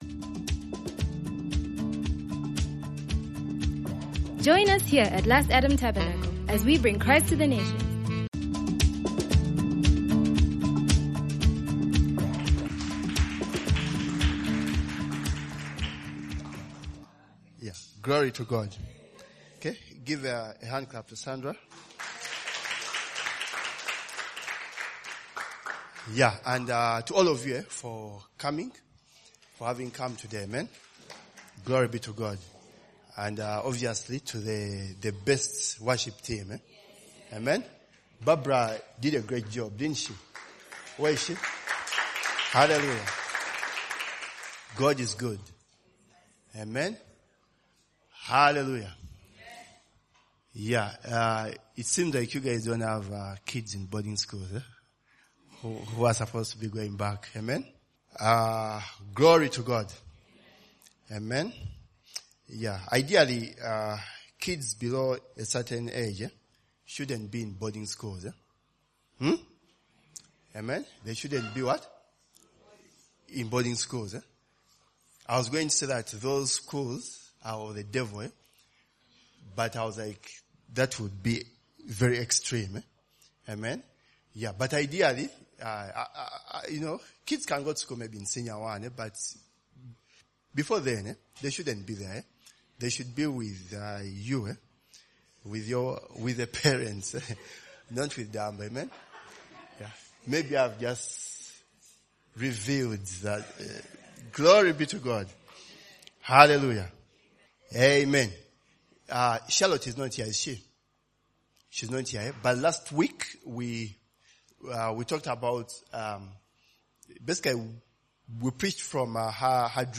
A sermon